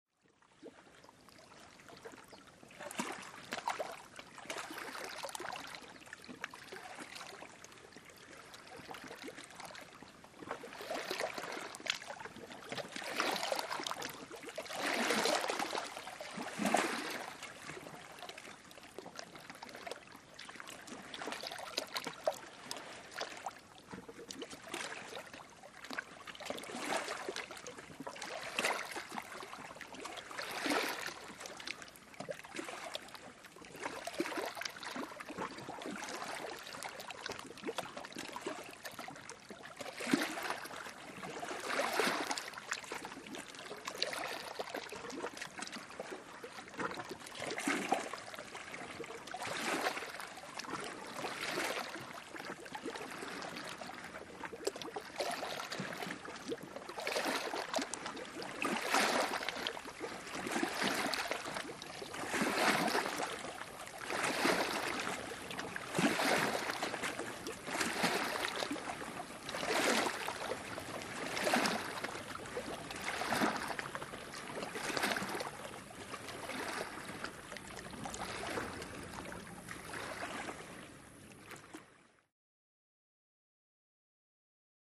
Light Steady Water Laps On A Calm Lake With Insects At Tail